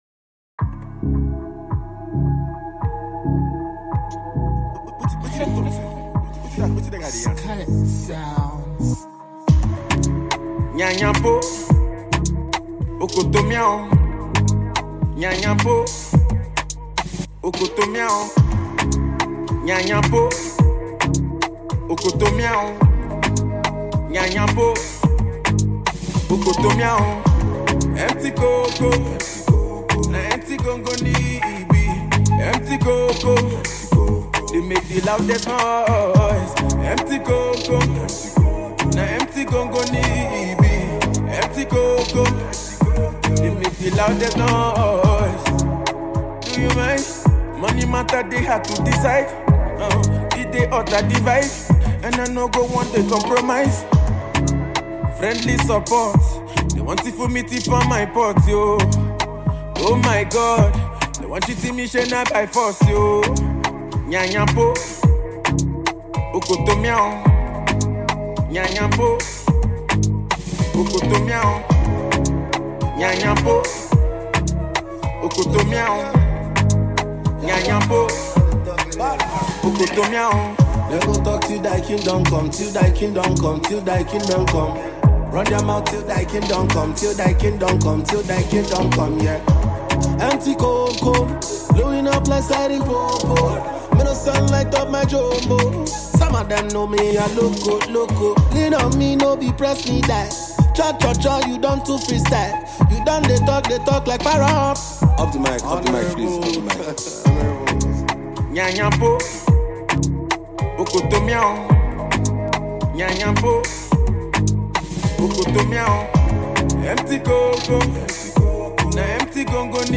Prominent Nigerian Singers